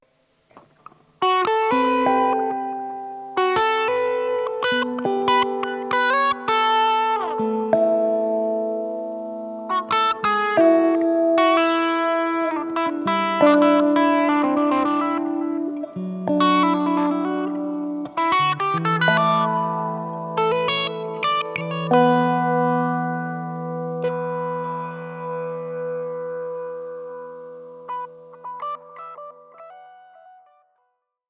zweite Spur dazu gespielt.